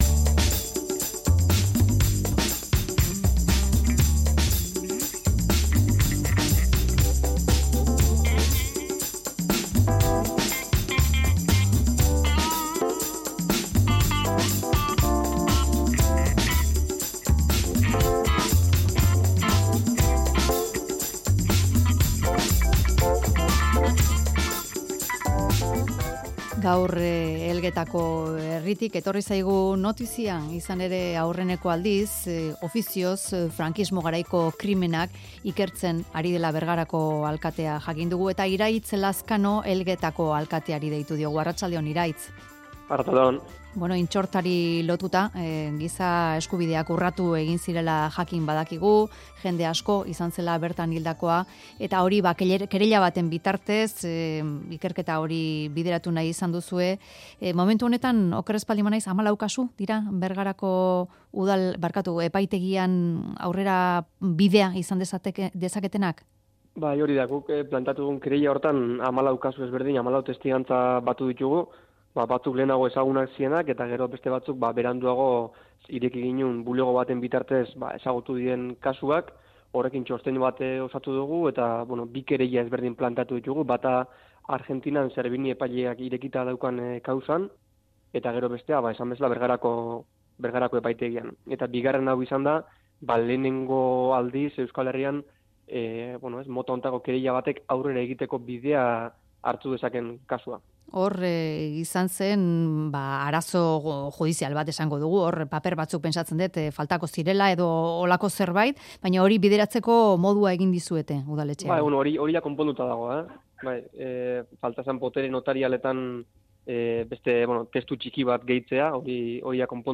Audioa: Epaile batek Frankismo garaiko krimenak ikertuko ditu lehen aldiz, genozidio eta gizateriaren aurkako delitu zantzuak ikusita. Elgetako Udalak jarri zuen kereila eta Iraitz Lazkano alkateak egin du balorazioa.